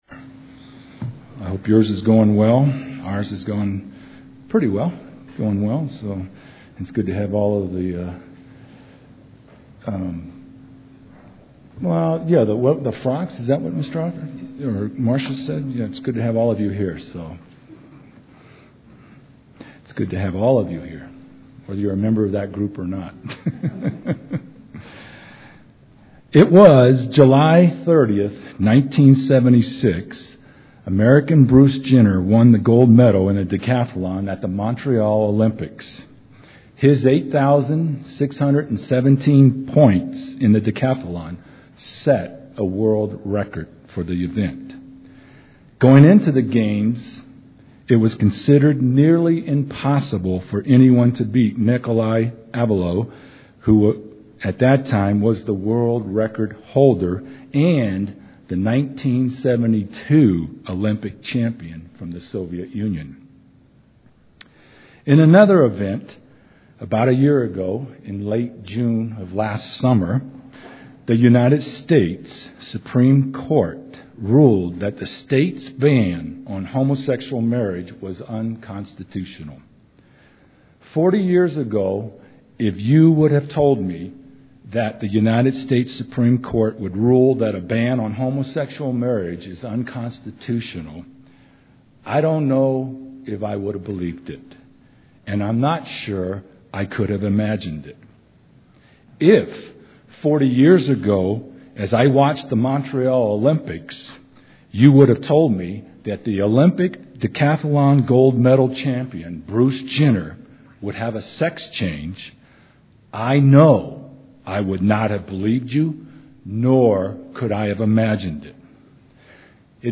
Sermons
Given in Colorado Springs, CO